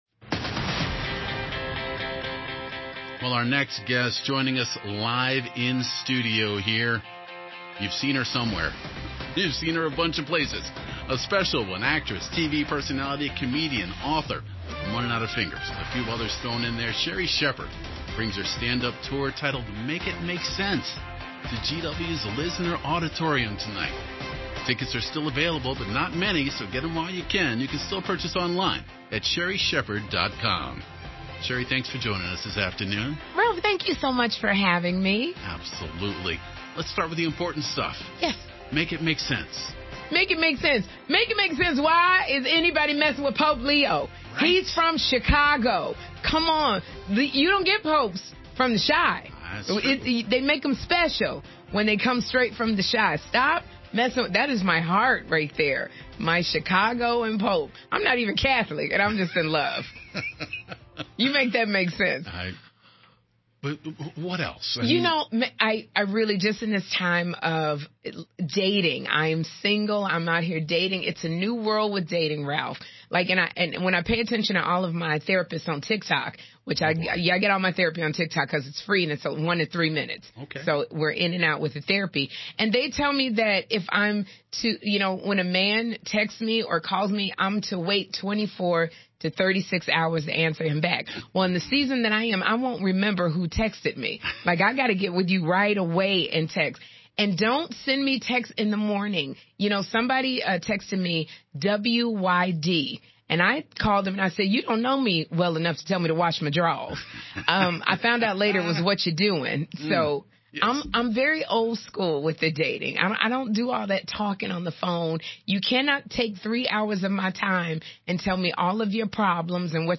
Actress, comedian, author and daytime talk show host Sherri Shepherd stopped by WTOP studios